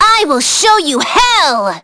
Yanne_L-Vox_Skill3.wav